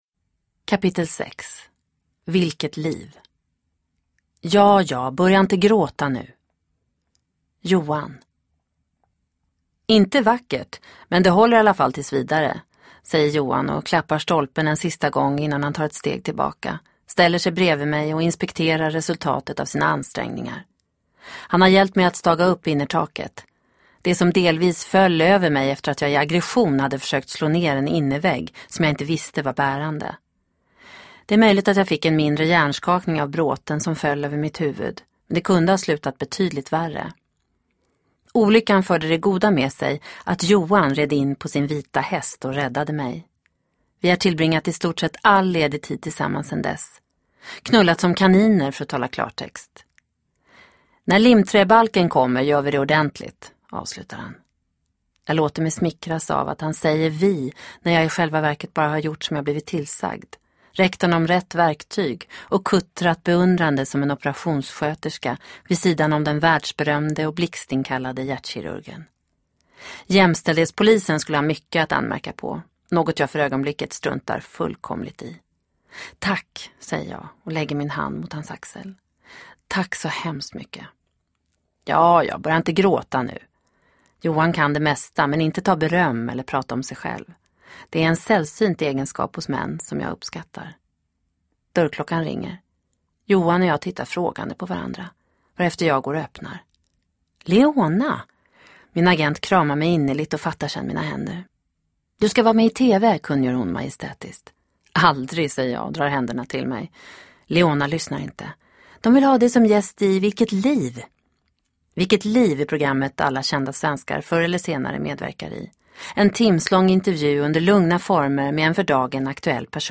Hemvändaren del 6 – Ljudbok